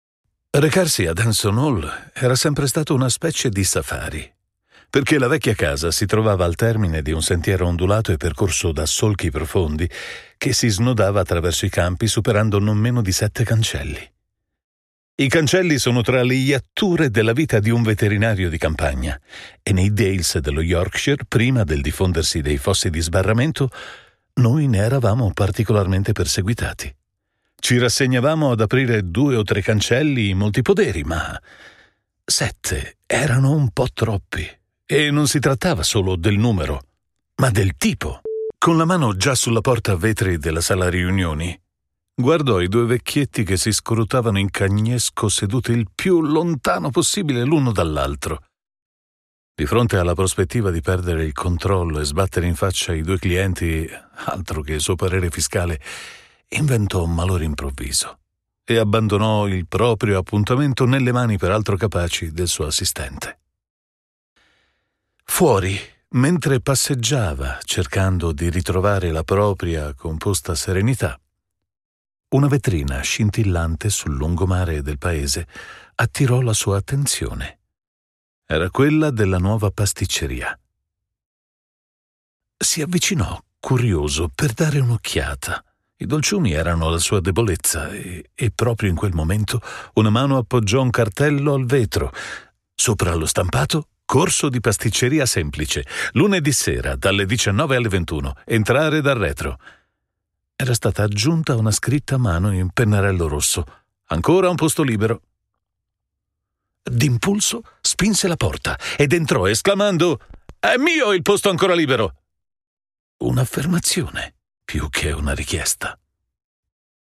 Today I produce from my perfectly equipped studio and record my voice for my clients, numerous over the years.
Sprechprobe: Sonstiges (Muttersprache):